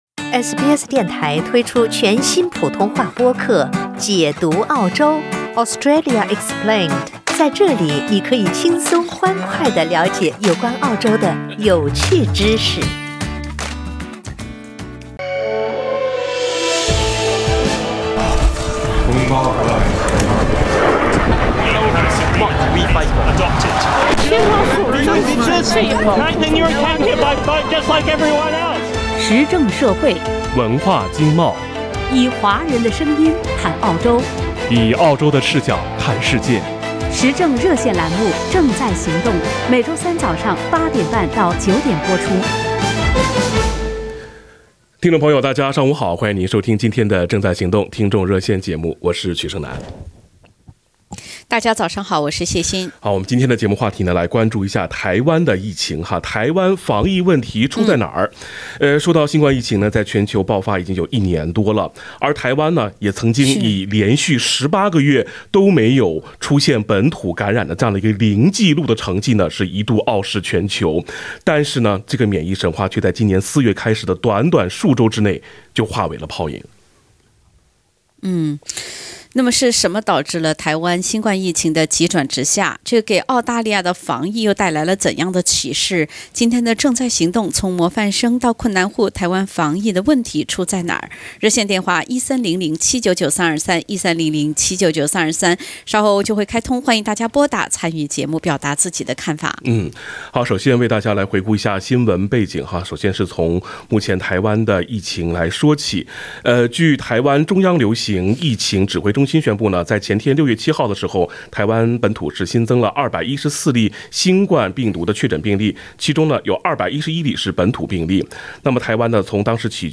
(點擊封面圖片，收聽完整熱線錄音）